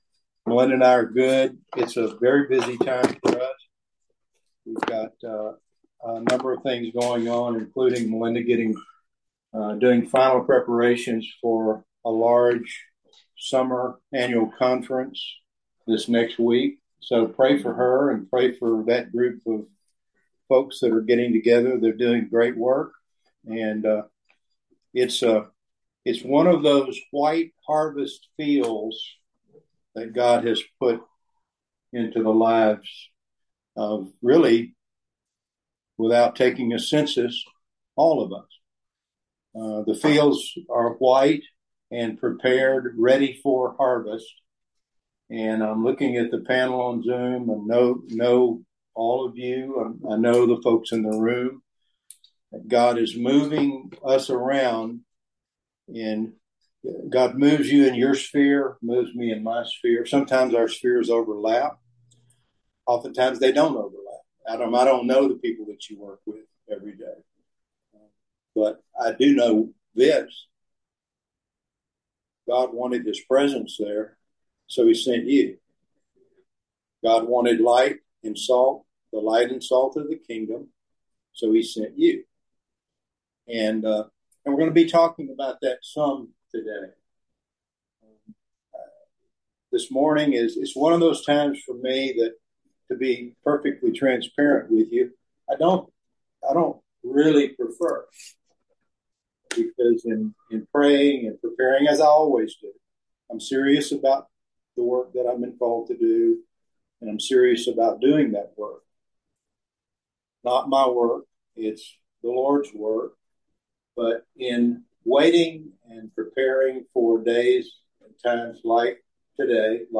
We had a wonderful gathering on Sunday, June 23rd.